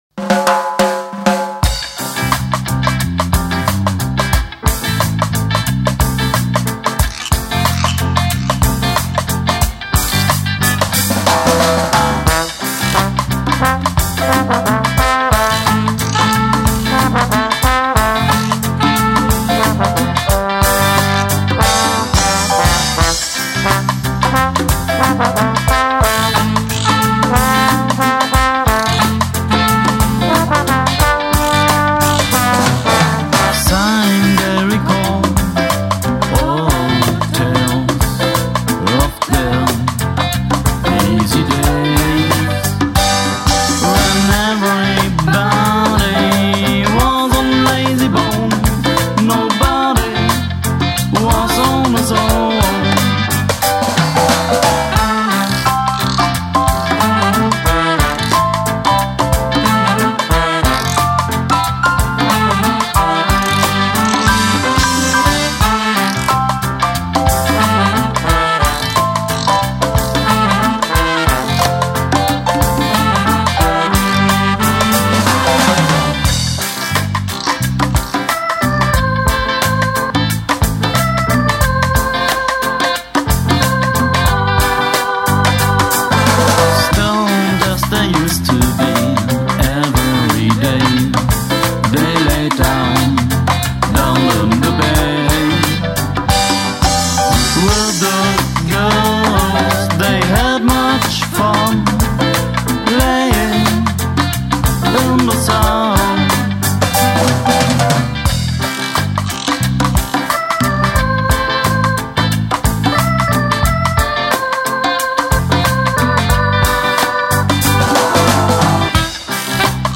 Ska since 1994 Ska und Jamaica gehören zusammen